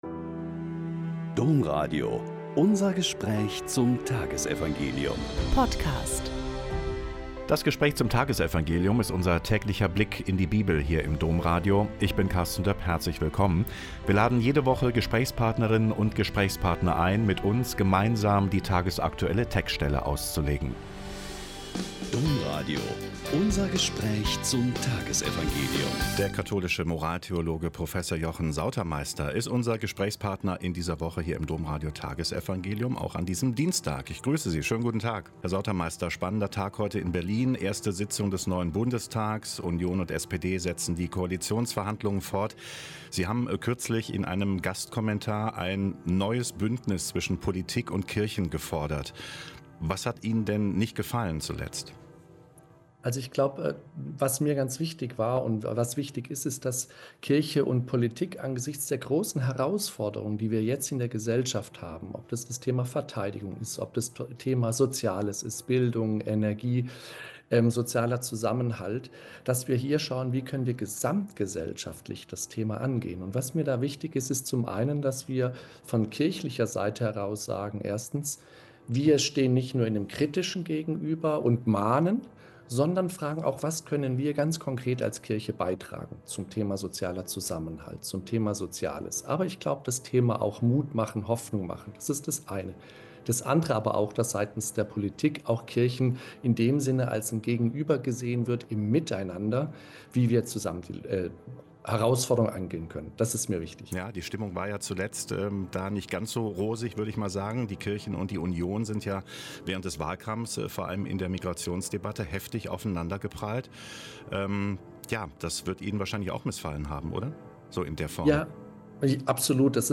Lk 1,26-38- Gespräch